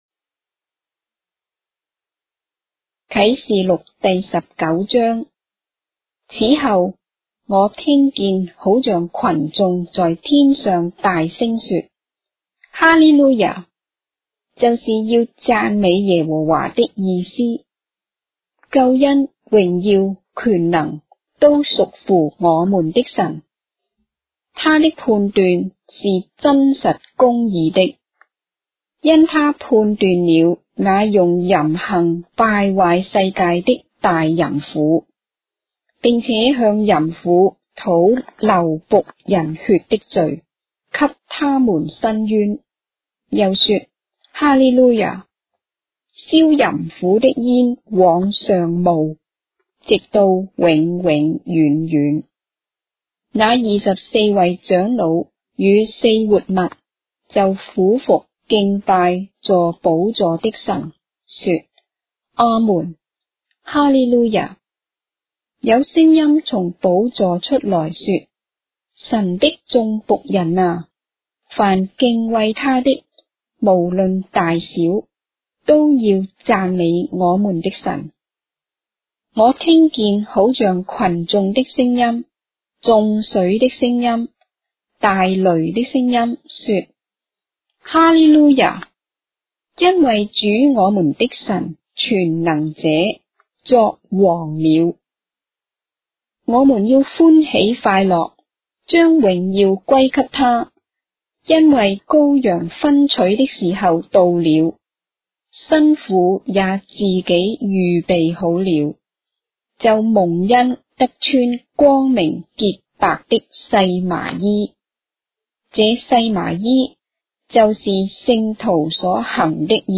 章的聖經在中國的語言，音頻旁白- Revelation, chapter 19 of the Holy Bible in Traditional Chinese